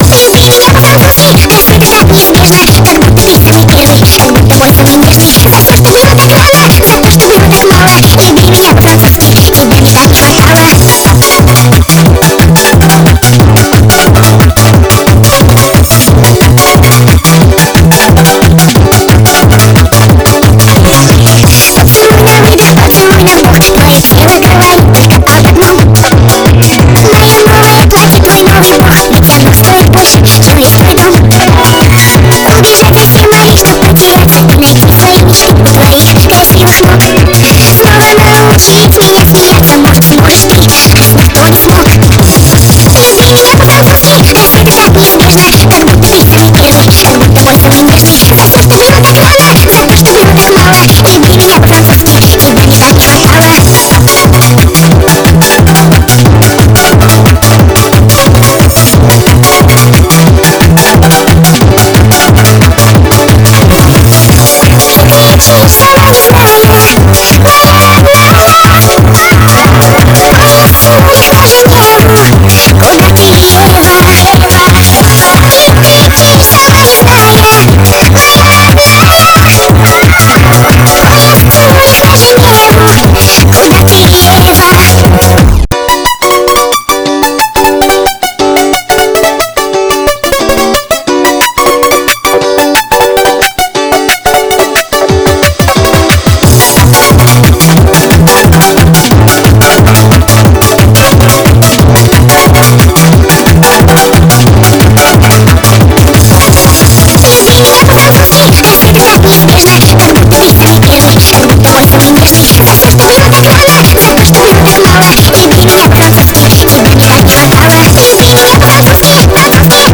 Nightcore